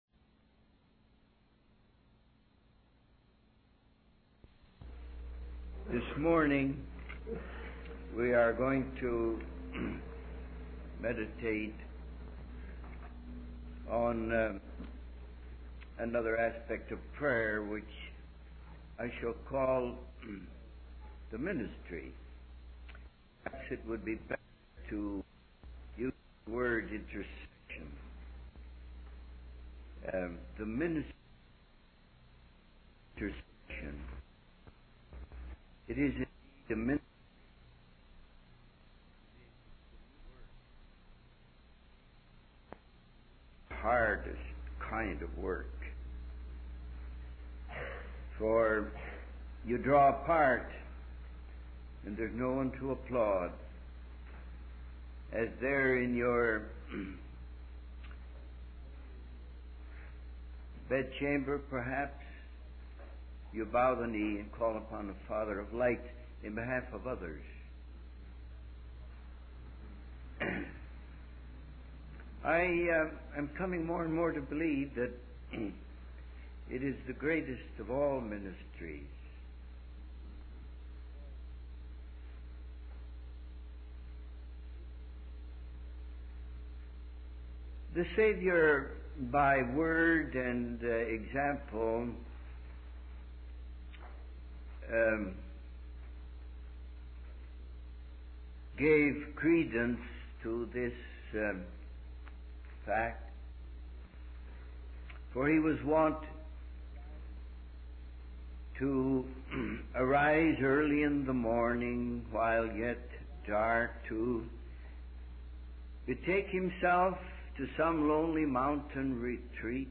In this sermon, the speaker emphasizes the importance of prayer and the ministry of the Word.